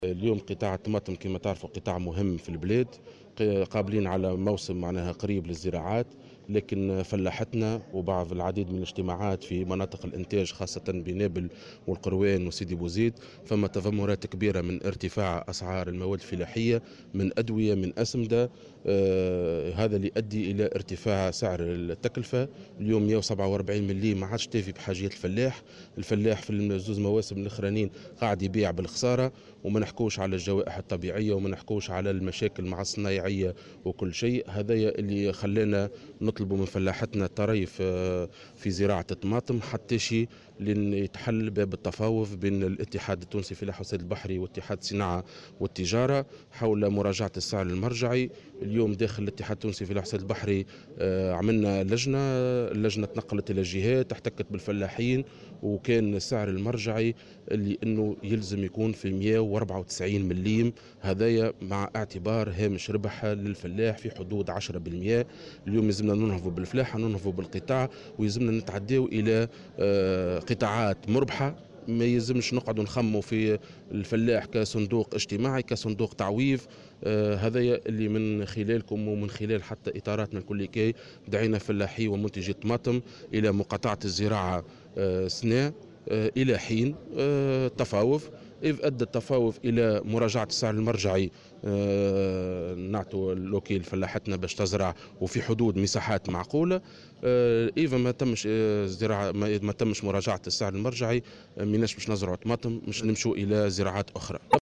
وأضاف في تصريح لمراسلة "الجوهرة أف أم" اليوم على هامش انعقاد المؤتمر الجهوي لاتحاد الفلاحة بنابل، أنه تم عقد اجتماعات مع المنتجين في نابل والقيروان وسيدي وبوزيد، الذين عبروا عن تذمّرهم من ارتفاع تكاليف الإنتاج ( الأدوية والأسمدة)، إضافة إلى الخسائر الناجمة عن الجوائح الطبيعية.